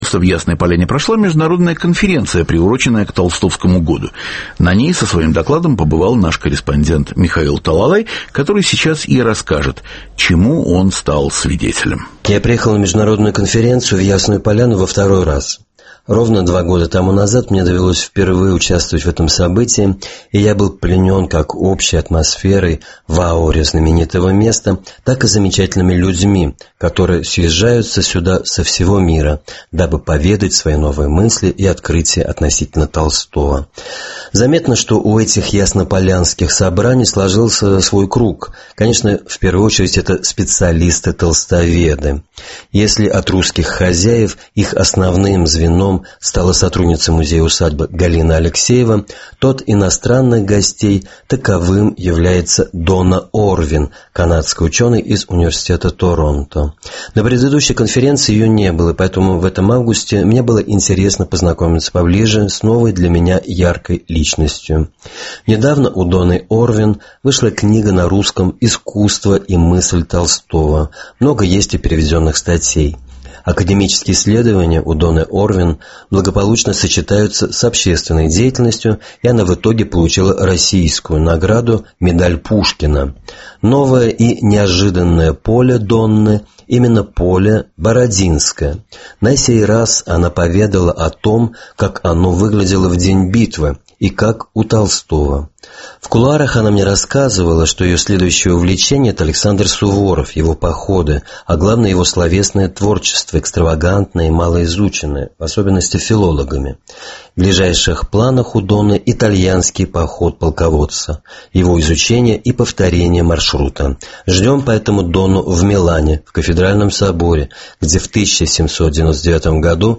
репортаж